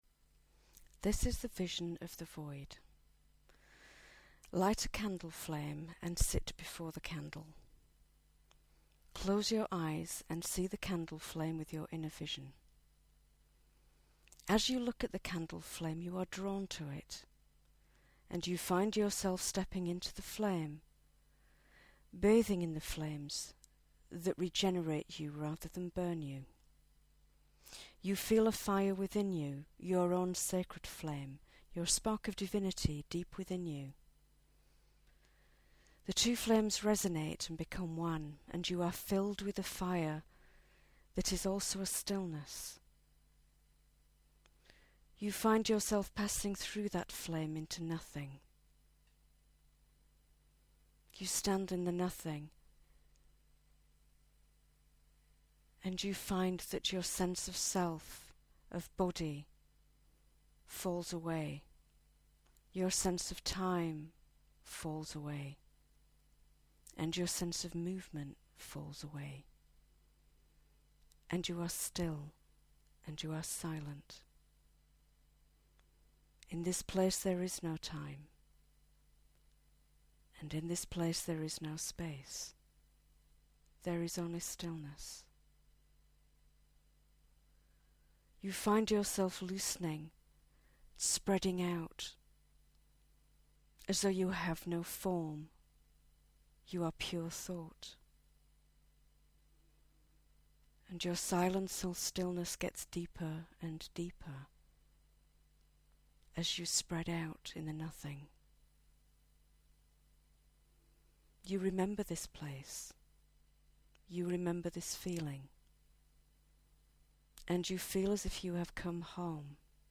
-simple recordings that you can use for basic meditations/visions